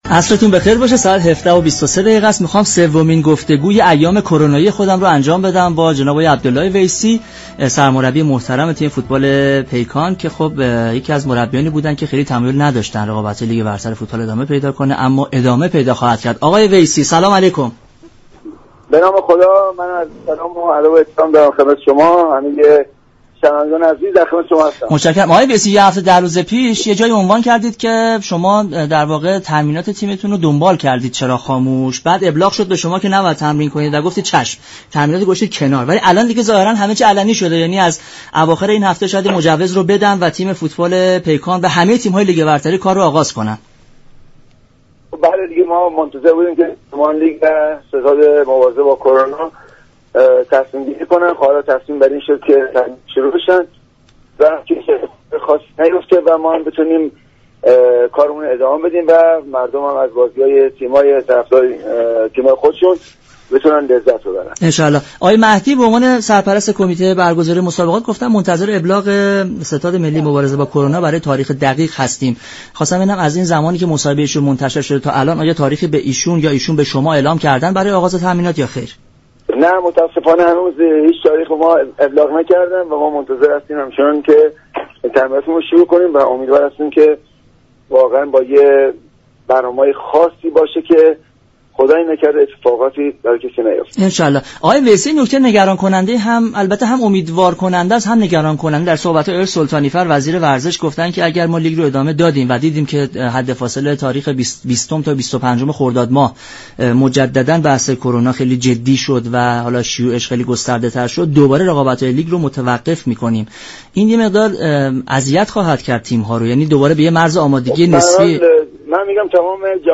عبدالله ویسی سرمربی تیم پیكان در برنامه ورزش ایران گفت: در شرایط كرونایی، امیدواریم برنامه ریزی ها طوری پیش رود كه هیچ شخصی دچار مشكل نشود.